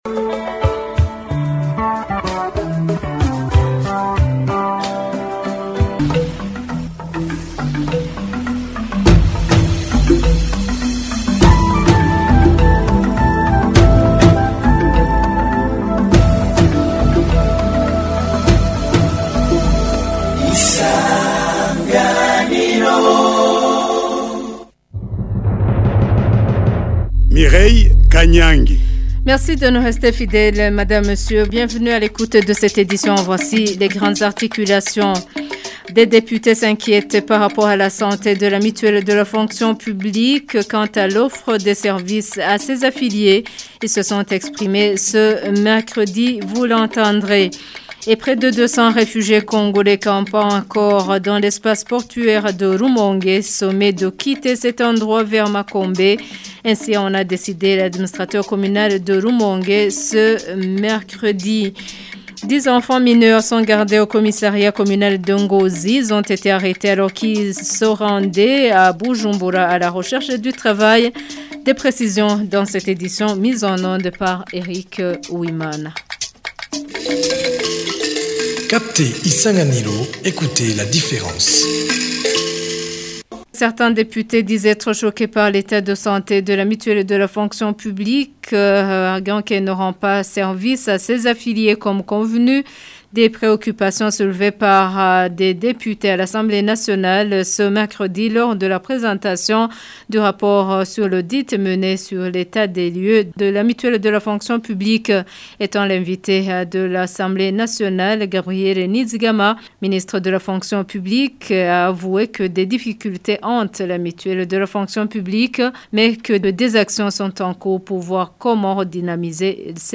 Journal du 7 janvier 2026